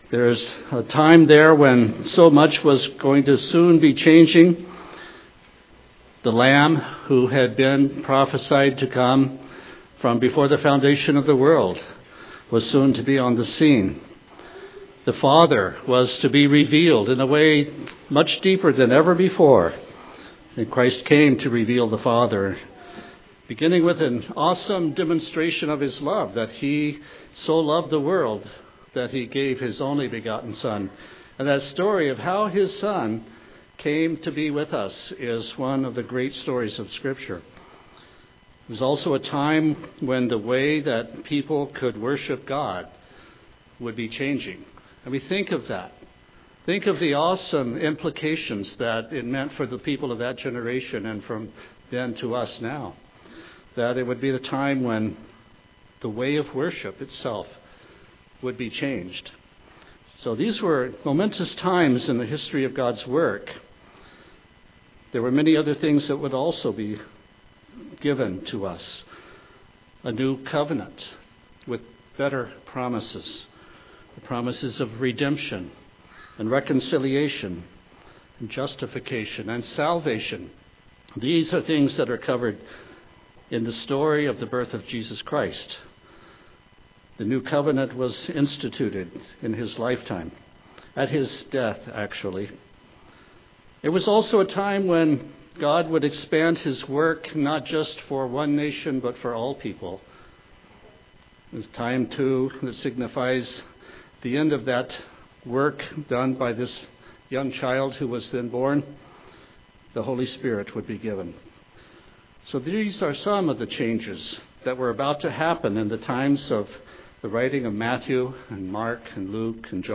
Given in Tacoma, WA
UCG Sermon Studying the bible?